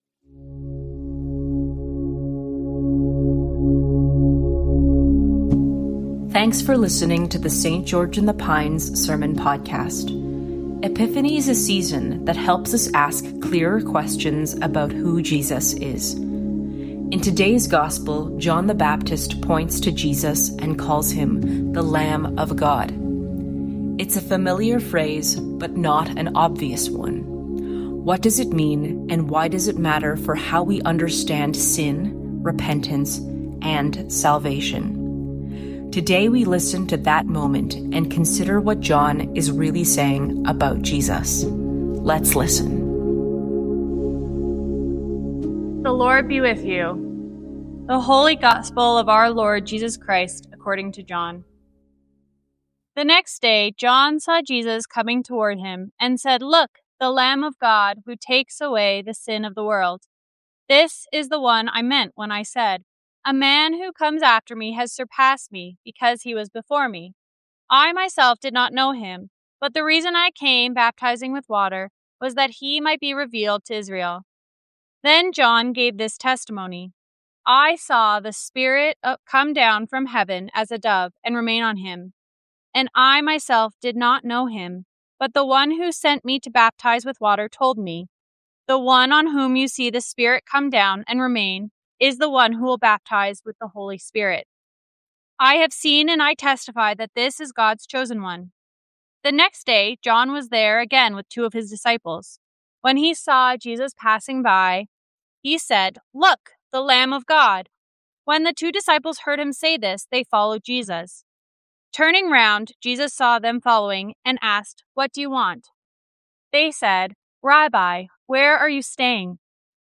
High Christology Isaiah 49:1-7; 1 Corinthians 1:1-9; John 1:29-42 Guest Speaker January 18, 2026 MANIFEST God’s glory is revealed in Jesus as he comes near to heal what was broken.